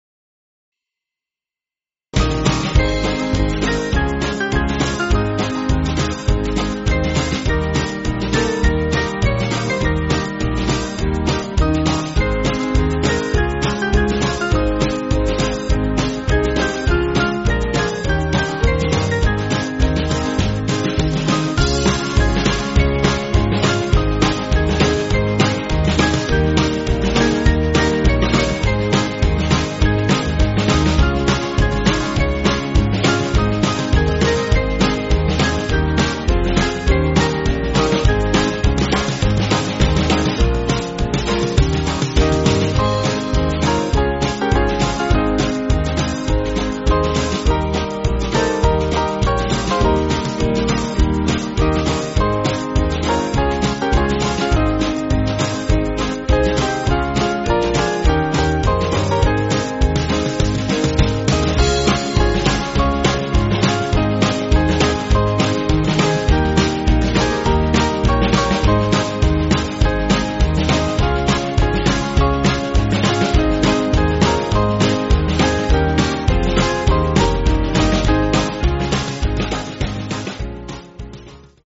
Small Band
(CM)   4/Ab